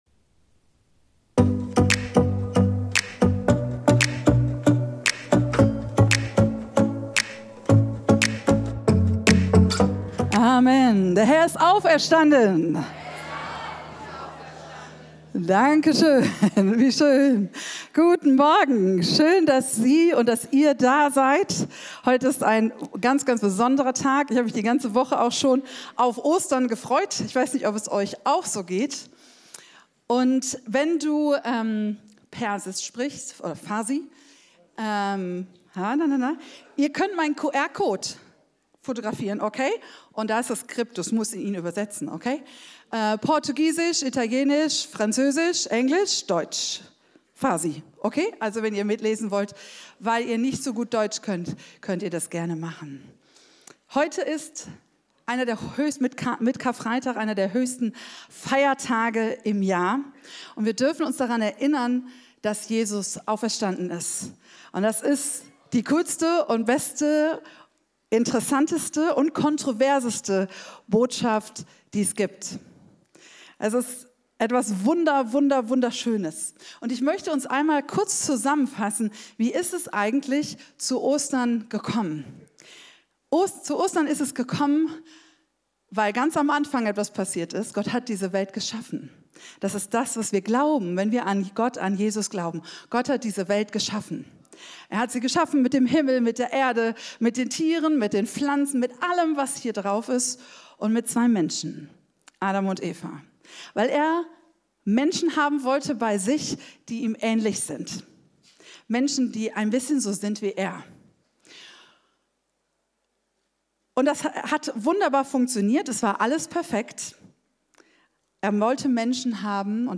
Live-Gottesdienst aus der Life Kirche Langenfeld.
Kategorie: Sonntaggottesdienst